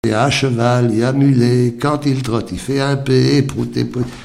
Mémoires et Patrimoines vivants - RaddO est une base de données d'archives iconographiques et sonores.
formulette enfantine : sauteuse
Pièce musicale inédite